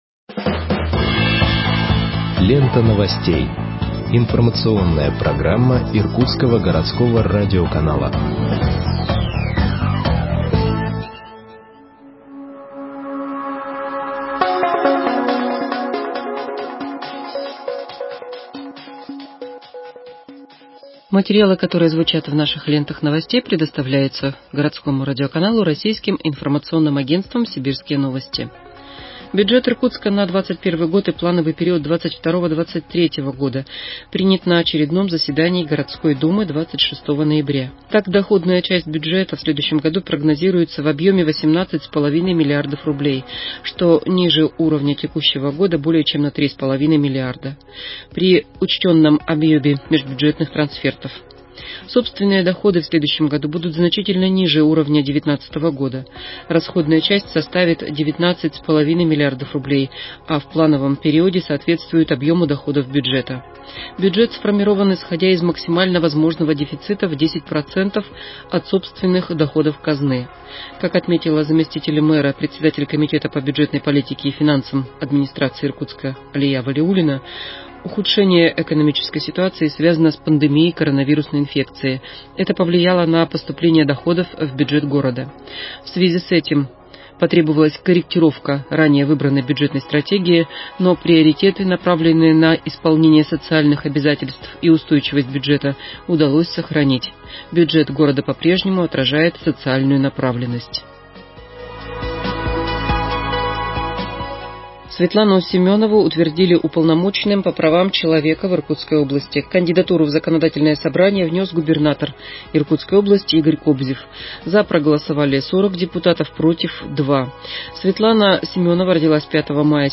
Выпуск новостей в подкастах газеты Иркутск от 30.11.2020